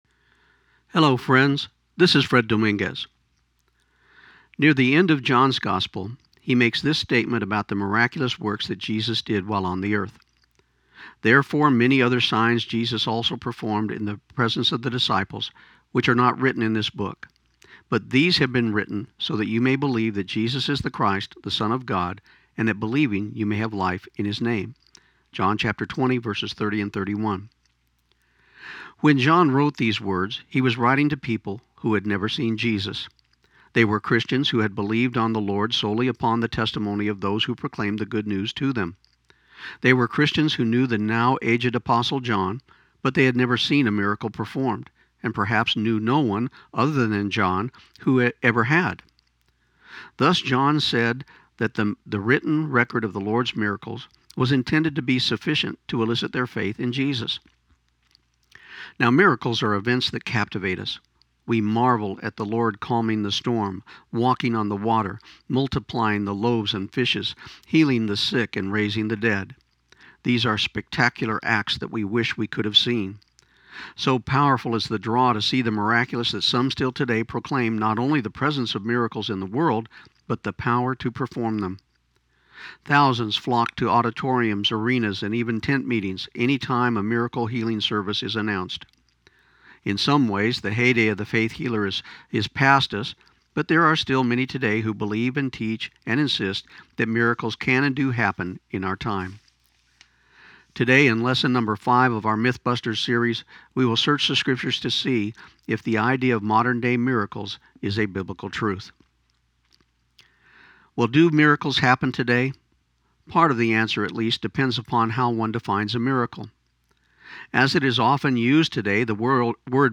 This program aired on KIUN 1400 AM in Pecos, TX on May 20, 2015.